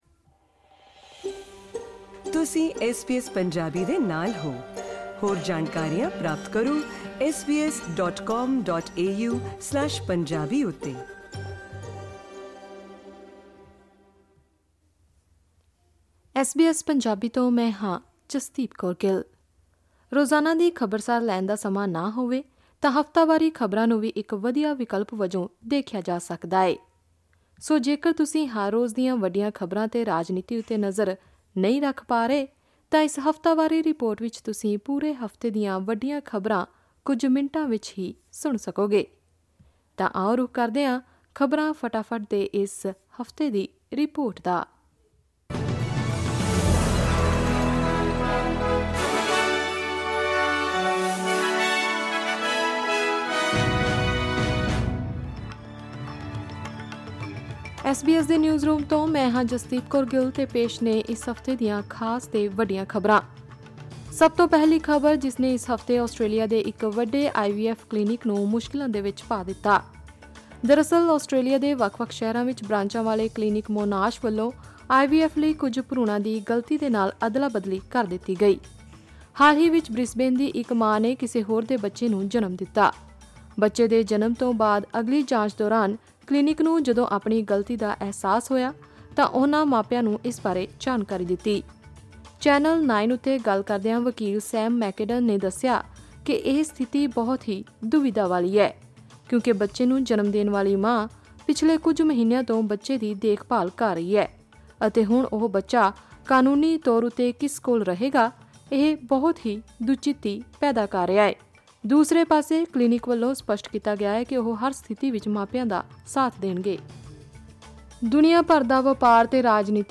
ਖਬਰਾਂ ਫਟਾਫੱਟ: ਆਸਟ੍ਰੇਲੀਆ 'ਚ ਗਲਤ ਭਰੂਣ ਦੀ ਸਪਲਾਈ ਤੋਂ ਭਾਰਤ 'ਚ ਤਹੱਵੁਰ ਰਾਣਾ ਦੀ ਪੇਸ਼ੀ ਤੱਕ ਦੁਨੀਆ ਭਰ ਦੀਆਂ ਹਫਤਾਵਾਰੀ ਖ਼ਬਰਾਂ